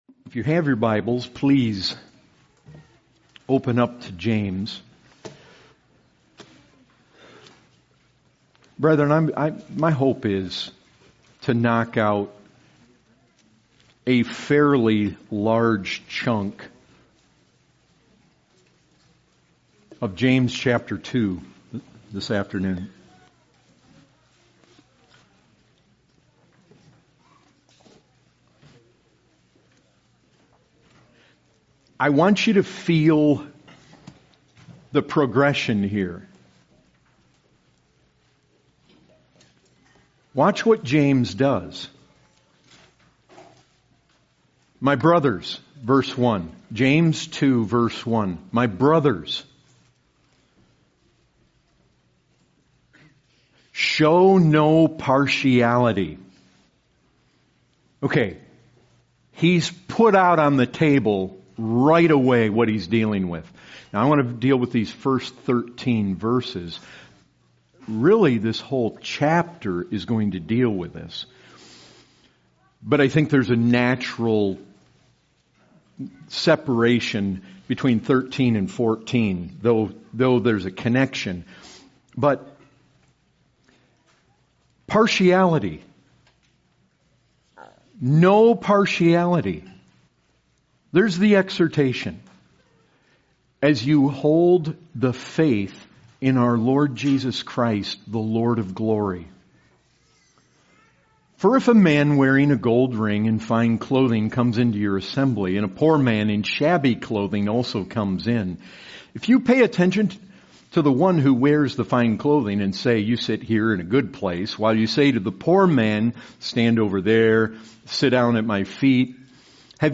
This sermon from James 2 invites us to reflect on the significance of partiality in our lives and its deeper implications.